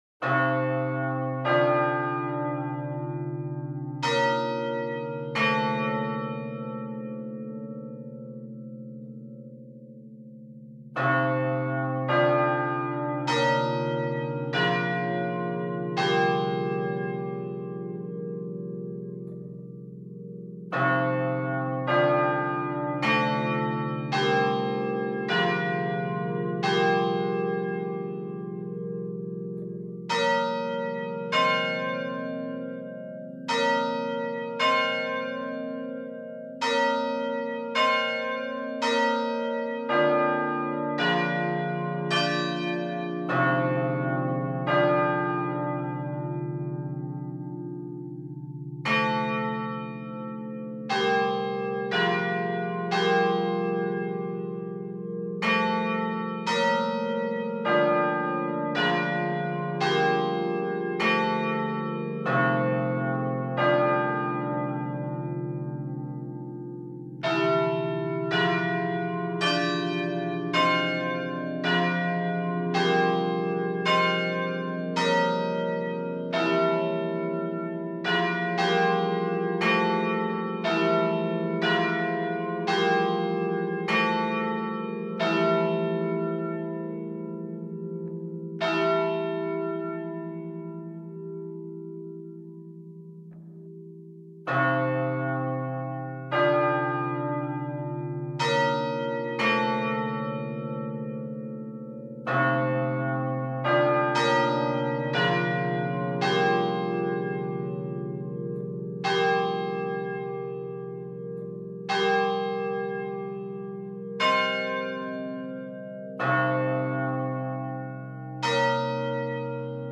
chimes Download PDF Duration